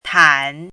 拼音： tǎn
注音： ㄊㄢˇ